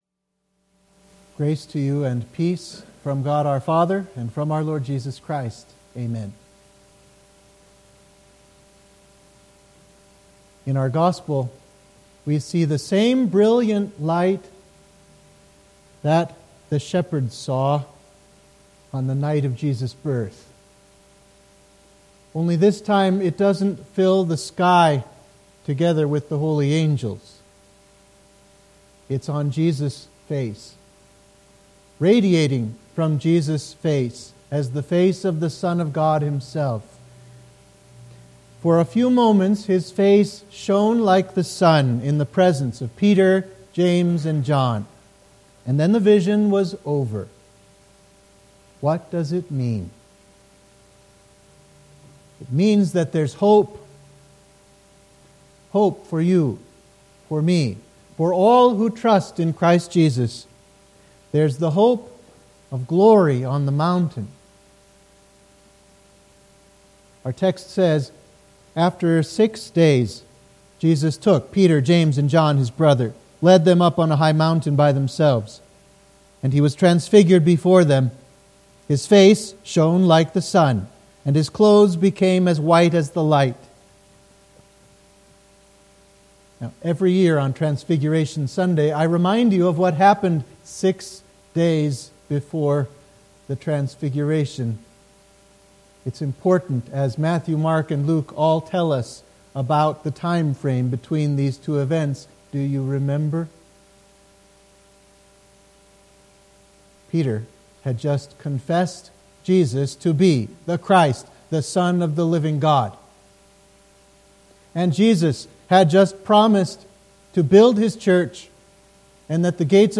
Sermon for the Transfiguration of Our Lord